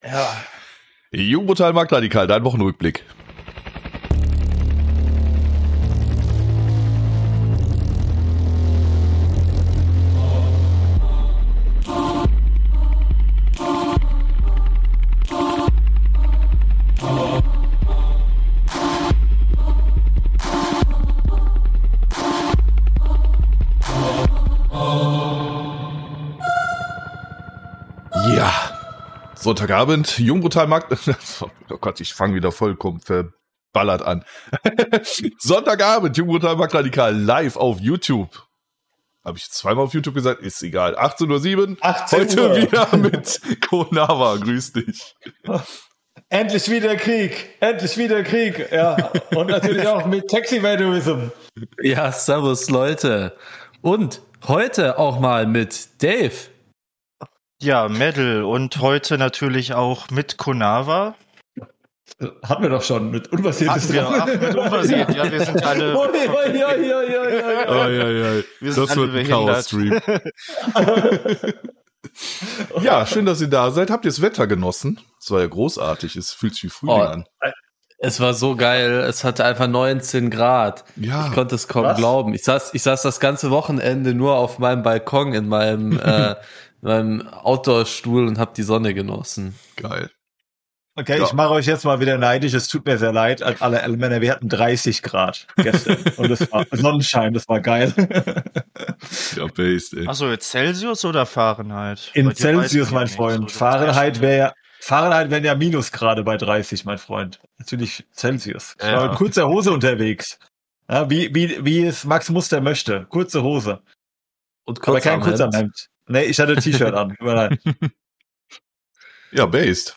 Wir beginnen die Sendung mit einem lockeren Austausch über das schöne Wetter der letzten Tage und teilen unsere Freizeitaktivitäten, bevor wir uns in brisante Themen stürzen.